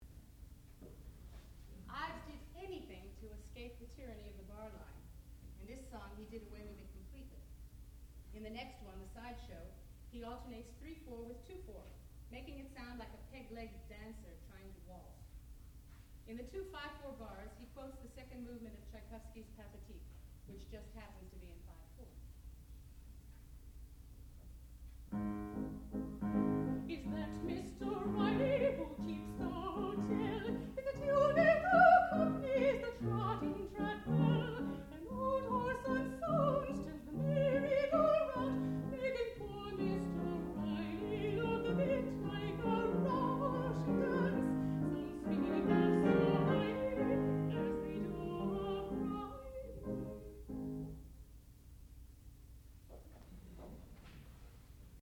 sound recording-musical
classical music
mezzo-soprano
piano